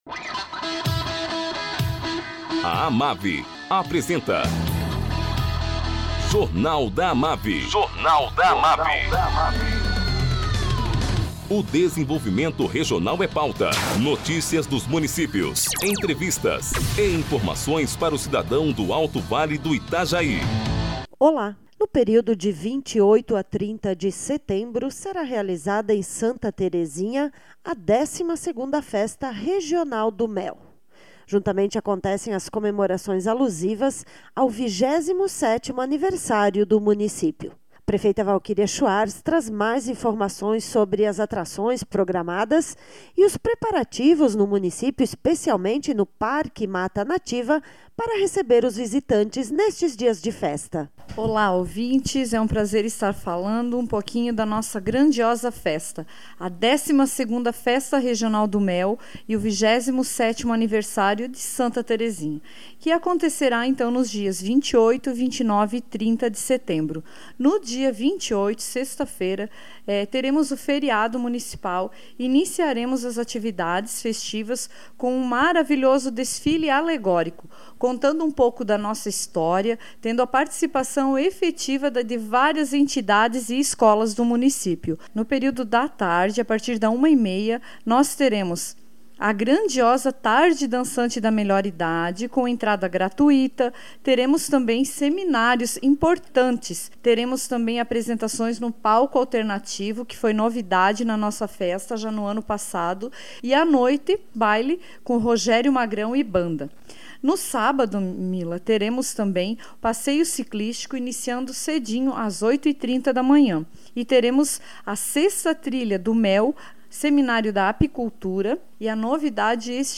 A prefeita de Santa Terezinha, Valquiria Schwarz, fala sobre a programação da 12ª Festa Regional do Mwel, que será realizada de 28 a 30 de setembro.